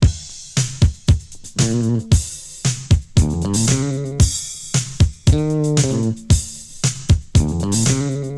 • 115 Bpm Drum Groove A Key.wav
Free drum groove - kick tuned to the A note. Loudest frequency: 1939Hz
115-bpm-drum-groove-a-key-gBt.wav